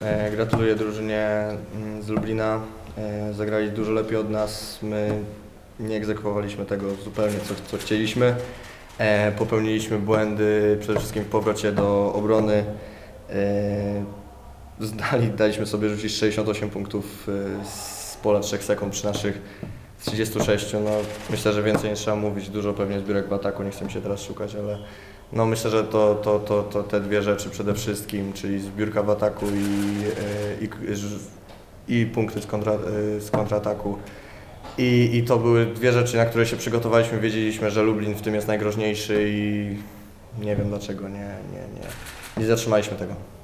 Zachęcamy do wsłuchania wypowiedzi przedstawicieli lubelskiej drużyny